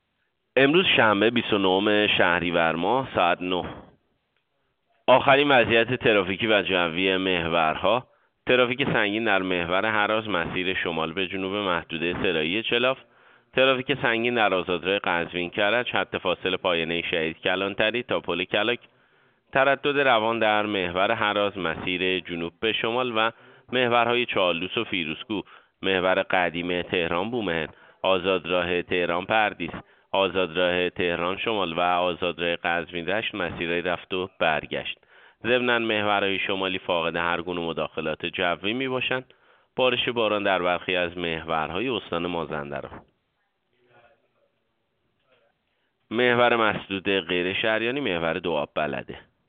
گزارش رادیو اینترنتی از آخرین وضعیت ترافیکی جاده‌ها ساعت ۹ بیست و نهم شهریور؛